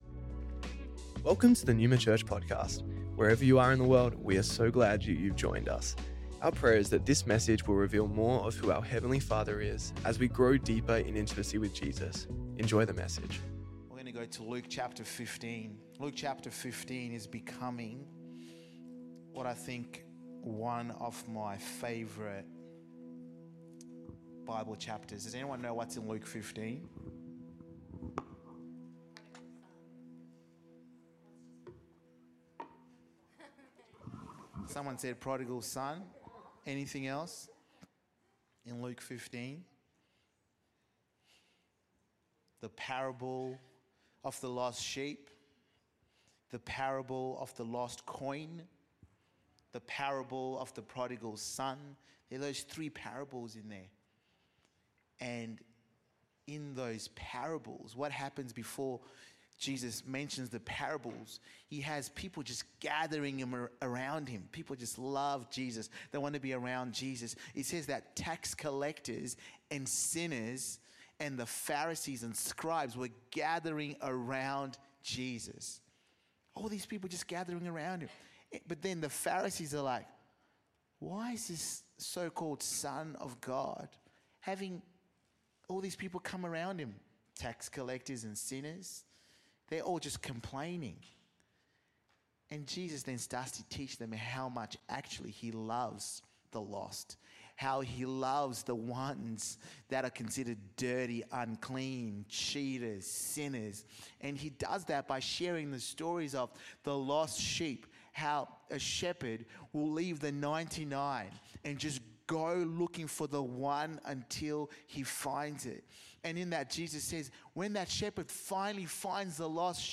Neuma Church Melbourne South Originally Recorded at the 10AM Service on Sunday 15th December 2024